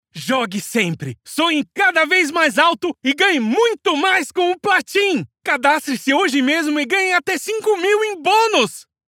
Announcements
Perfect Acoustic Booth
BaritoneBassLowVery Low